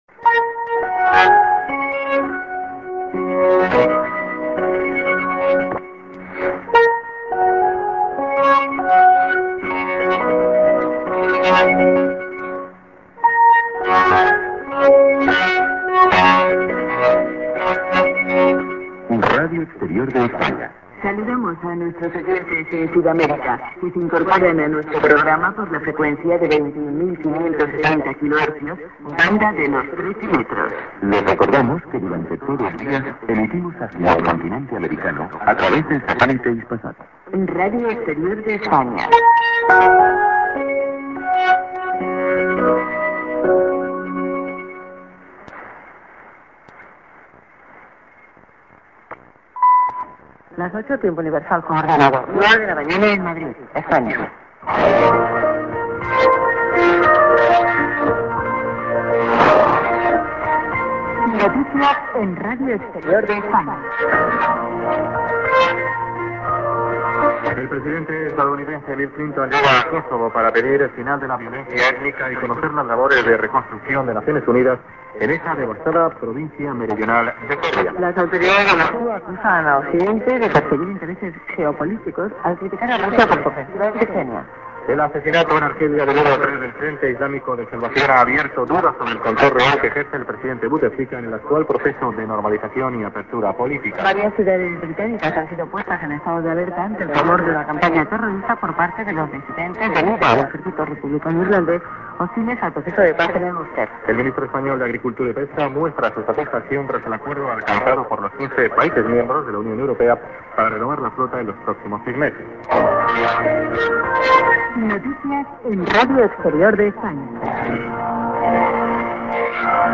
St. IS->ID+SKJ(man+women)->TS->ID(women)